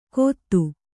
♪ kōttu